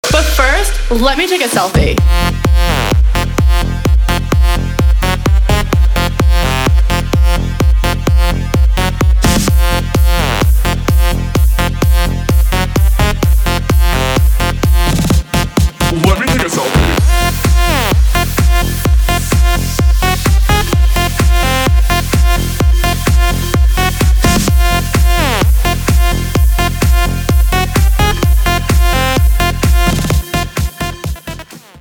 женский голос
dance
Electronica
club
качающие